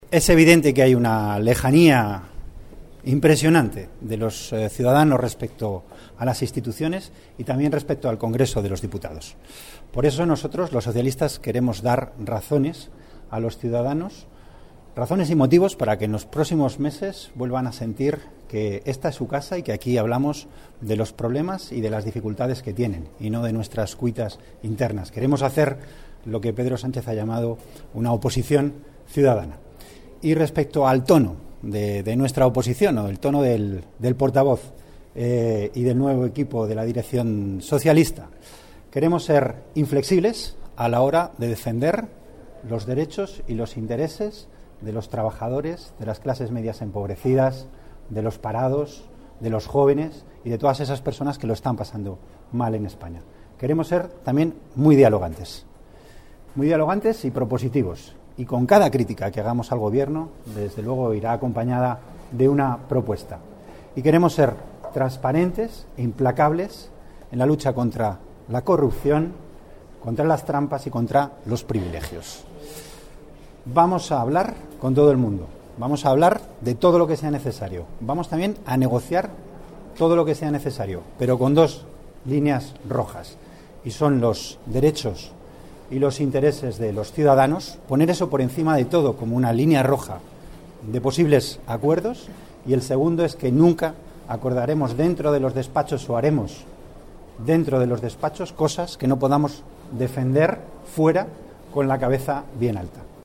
Primeras declaraciones de Antonio Hernando como portavoz del Grupo Parlamentario Socialista 9/09/2014